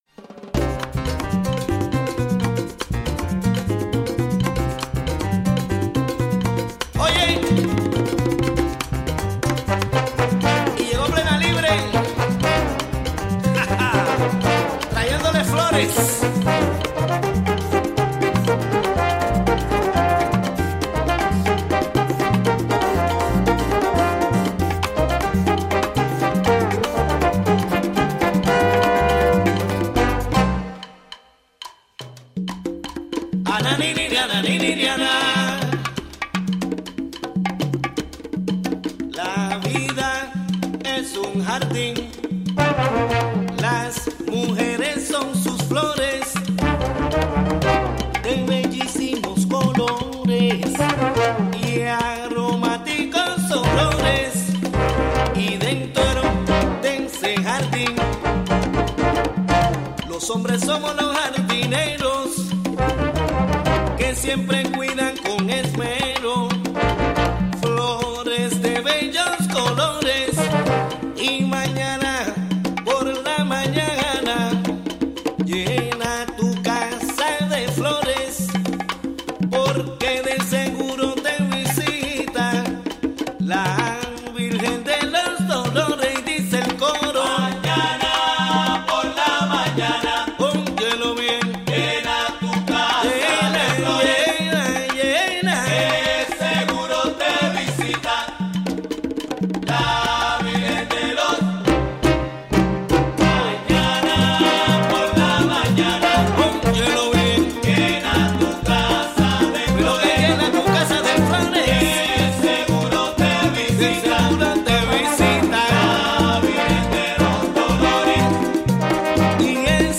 Weekly Spanish language radio news show featuring interviews, commentary, calendar of events and music. In this broadcast, a conversation about popular swimming spots in the Hudson Valley.